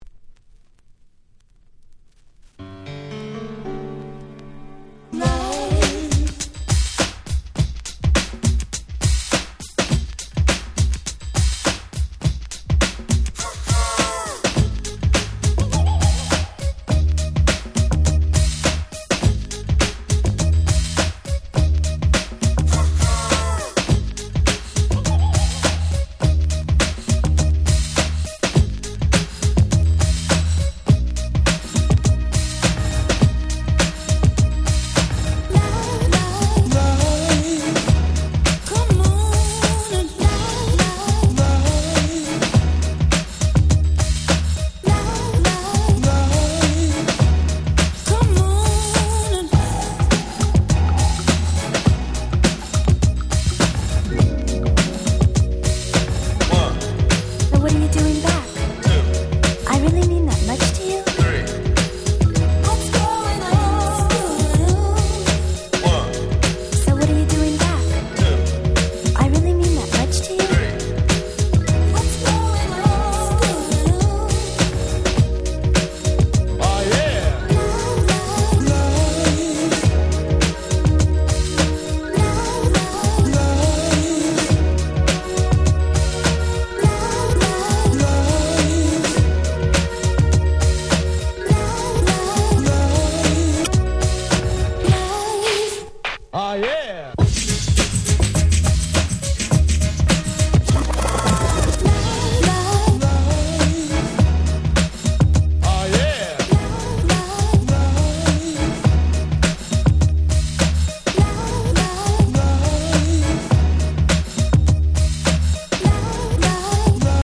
90年という年代柄、内容は流行りのGround Beatが多め。
グラウンドビート グランド Grand グラビ